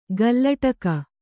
♪ gallaṭaka